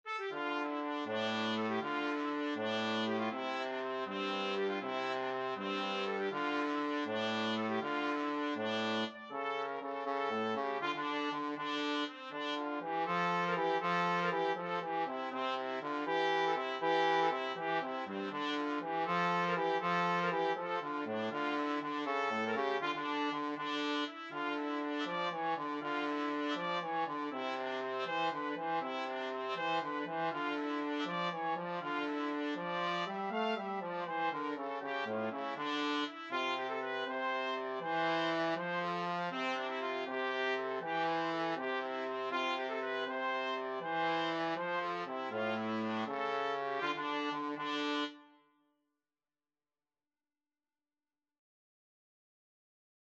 Free Sheet music for Trumpet-Trombone Duet
6/8 (View more 6/8 Music)
D minor (Sounding Pitch) E minor (Trumpet in Bb) (View more D minor Music for Trumpet-Trombone Duet )